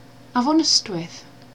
Der Ystwyth (walisisch Afon Ystwyth [avɔˈnəstwɪθ